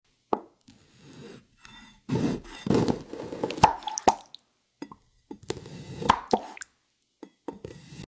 AI generated ASMR to cool your sound effects free download
AI-generated ASMR to cool your brain watch ‘til the end 👀🔪